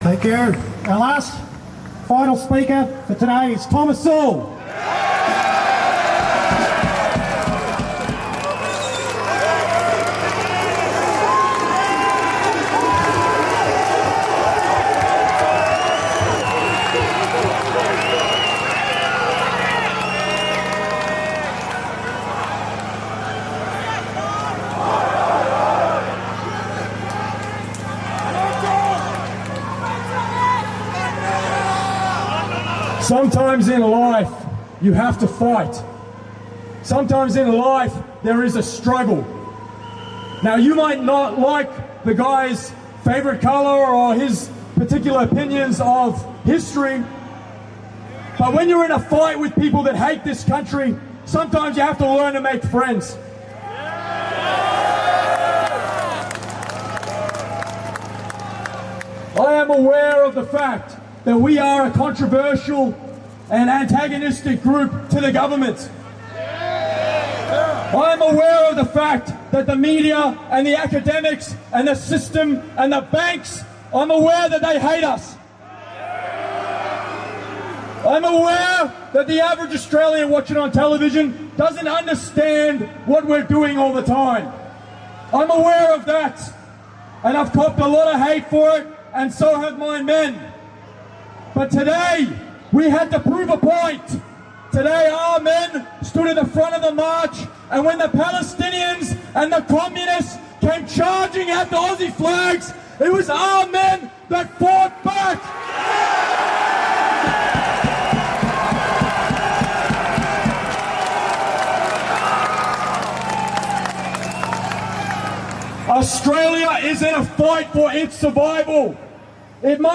Thomas Sewell Outside of the Victorian Parliament 31st August 2025